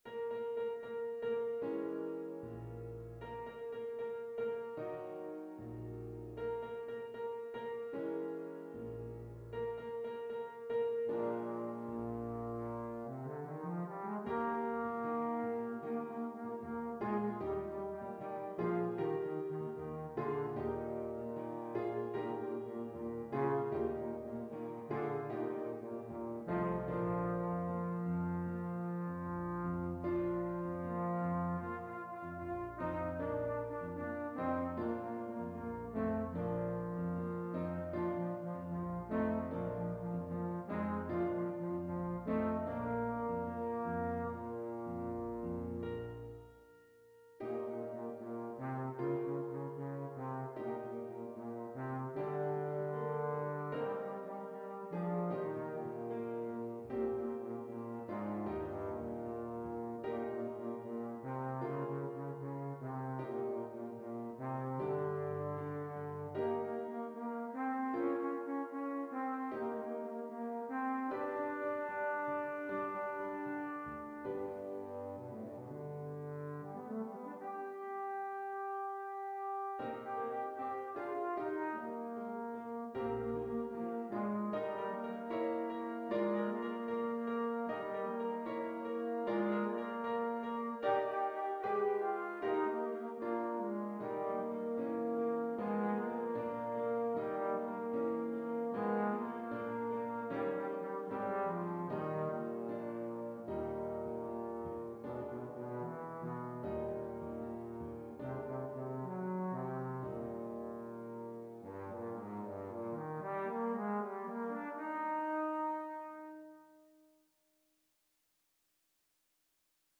Trombone
Eb major (Sounding Pitch) (View more Eb major Music for Trombone )
2/4 (View more 2/4 Music)
=76 Allegretto lusinghiero =104
G3-G5
Classical (View more Classical Trombone Music)
saint_saens_havanaise_op83_TBNE.mp3